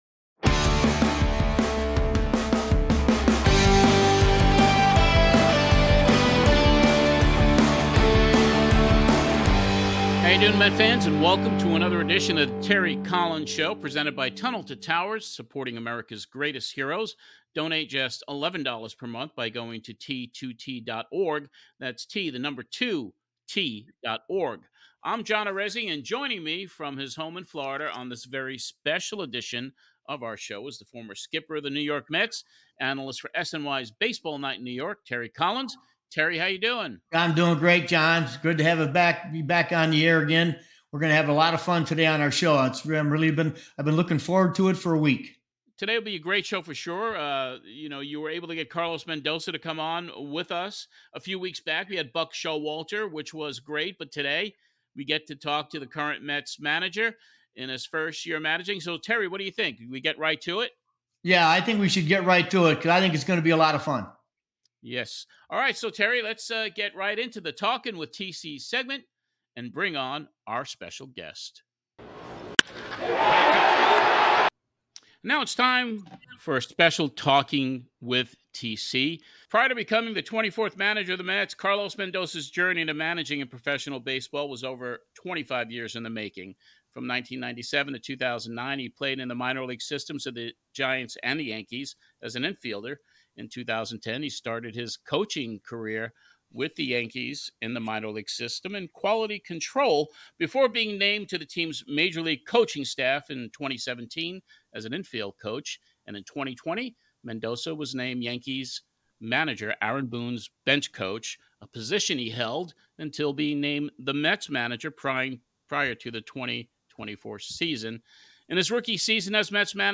The 2 skippers discuss the Mets 2024 season as only two managers can!